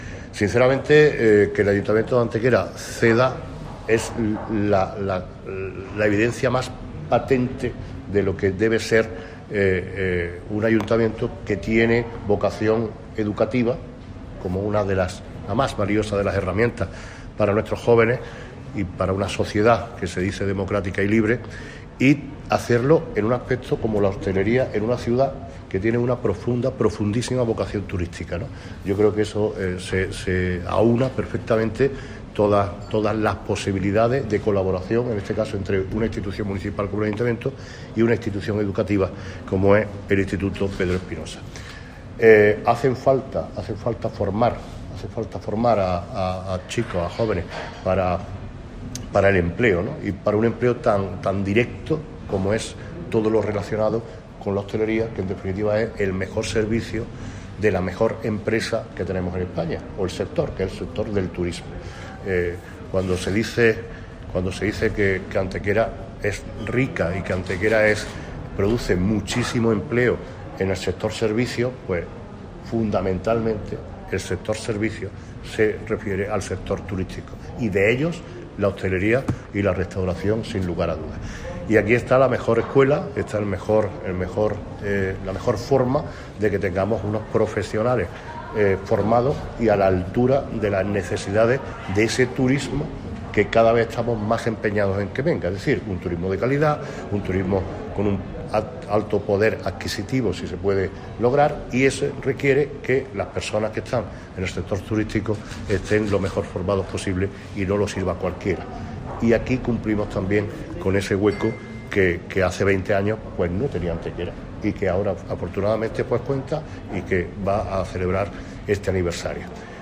Y para eso se requiere que los profesionales del sector estén lo mejor formados posibles", afirmó Manolo Barón durante la rueda de prensa de presentación.
Cortes de voz